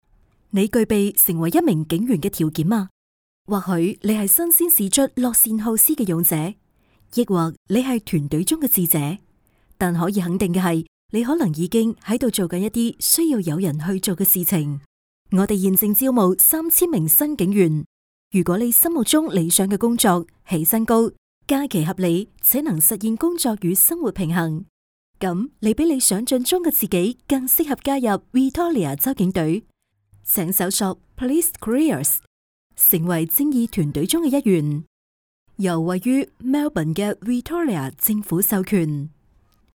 女粤17_广告_招聘_Victoria州警隊招募_年轻.mp3